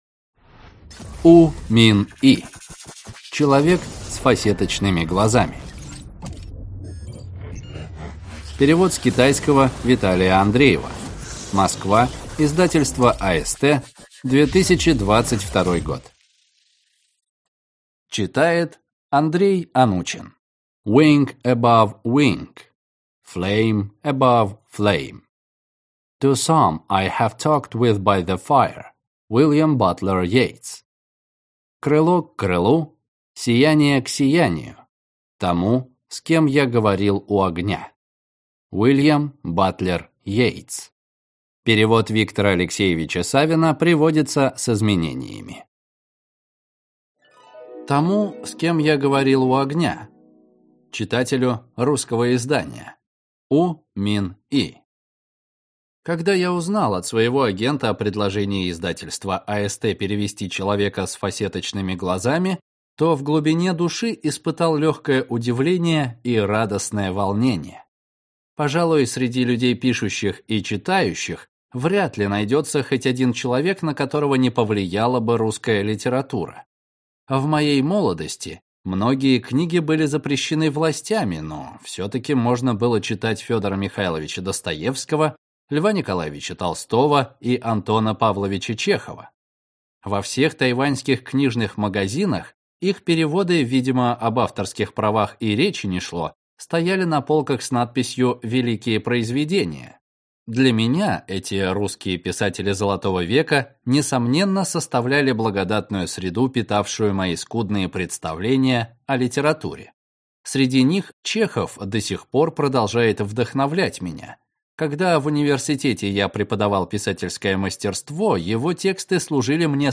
Аудиокнига Человек с фасеточными глазами | Библиотека аудиокниг